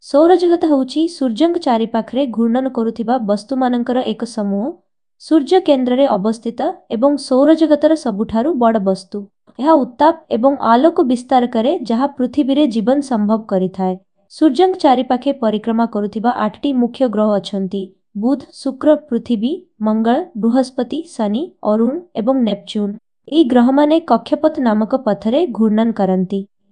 But what impressed me the most is its "Text to Speech" tool.
To test further, I gave it the below paragraph in English, asked it to translate to Odia, and then converted it to an audio.
I don't read the Odia text, but I know how to speak, and can say that the audio quality is very good. It speaks perfectly as they speak on the news channels.